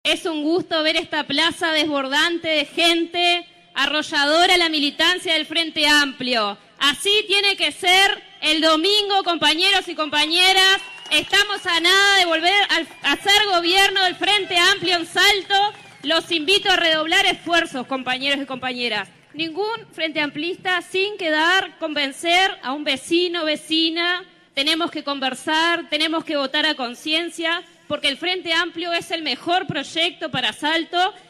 Multitudinario acto en la Plaza Estigarribia